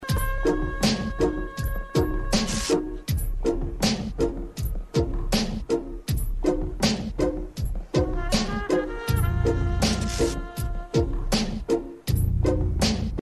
to początkowy utwór instrumentalny na trąbce...